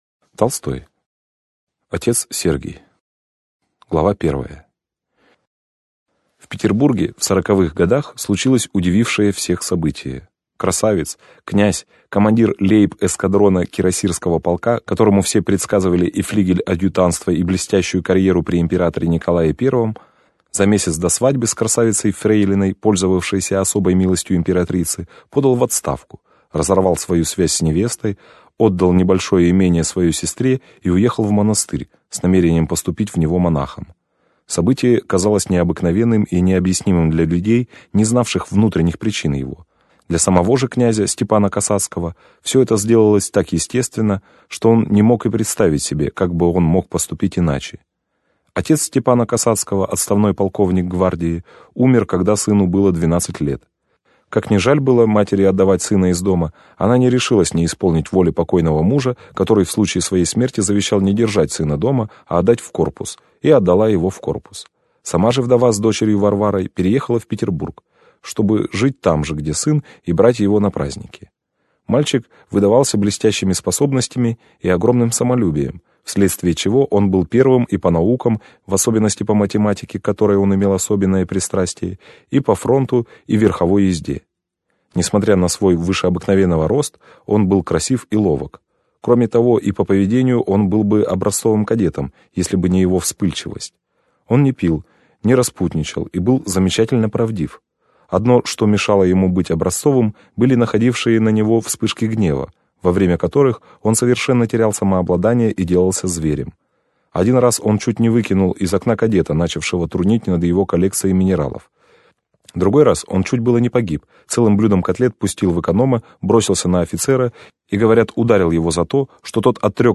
Аудиокнига Отец Сергий - купить, скачать и слушать онлайн | КнигоПоиск